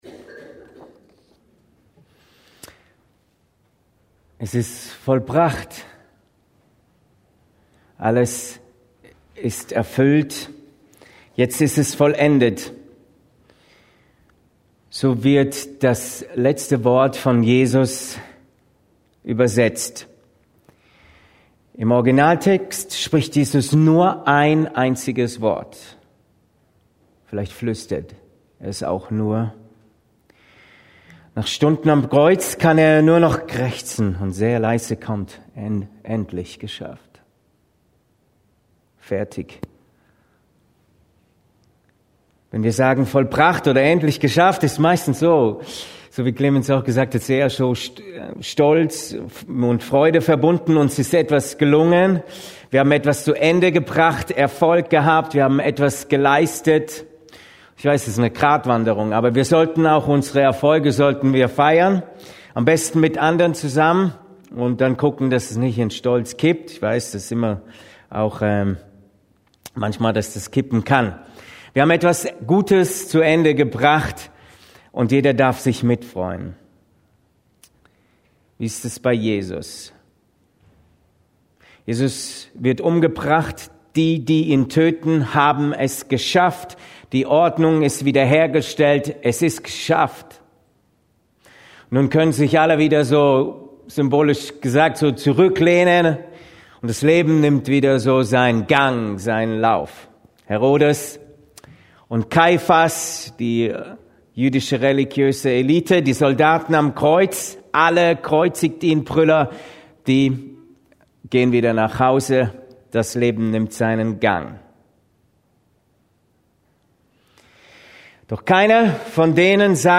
Letzte Worte von Jesus am Kreuz (Teil 2) – Predigten: Gemeinschaftsgemeinde Untermünkheim